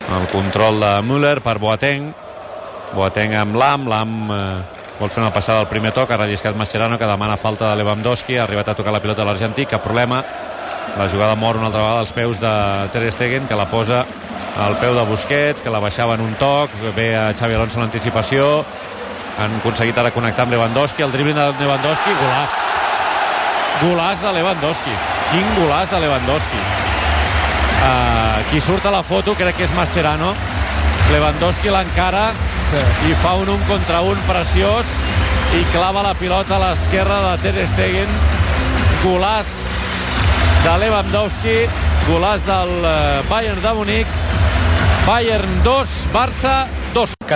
Transmissió del partit de tornada de la fase eliminatòria de la Copa d'Europa de futbol masculí entre el Bayern München i el Futbol Club Barcelona.
Narració del segon gol del Bayern München, marcat per Robert Lewandowski.
Esportiu